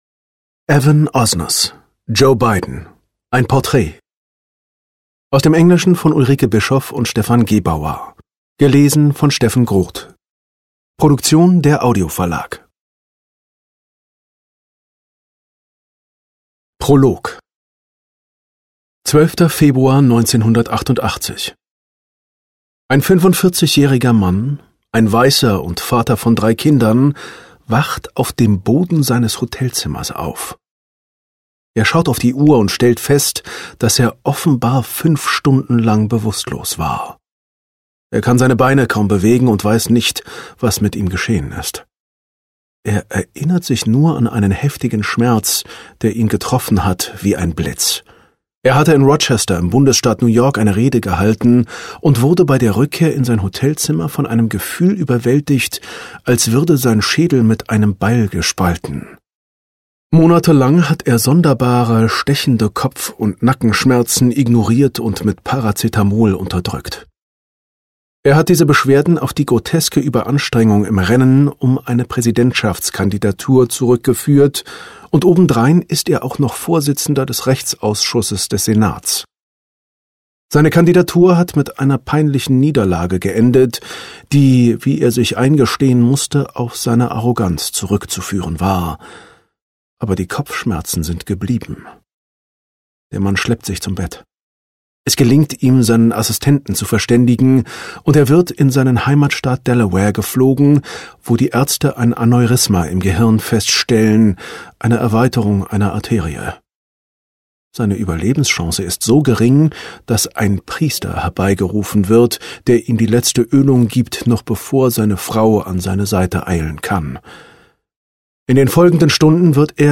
Hörbuch: Joe Biden.
Joe Biden. Ein Porträt Ungekürzte Lesung